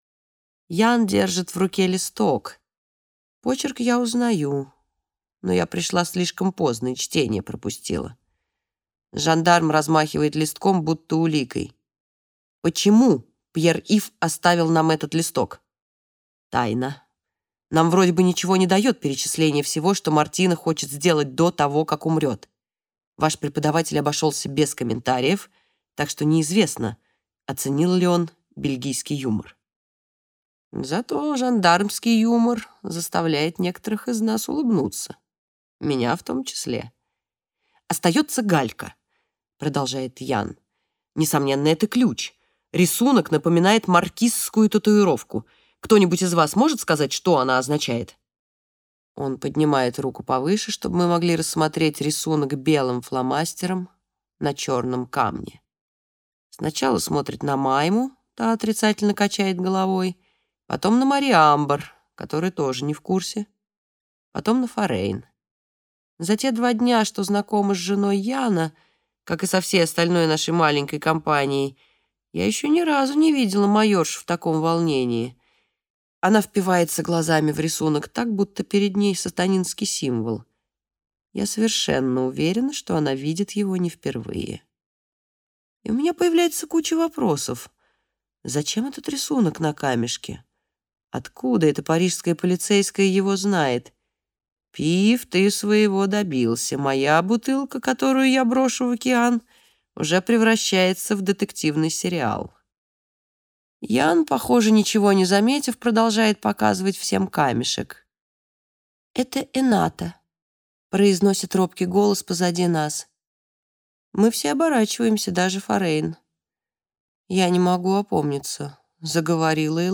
Аудиокнига Под опасным солнцем | Библиотека аудиокниг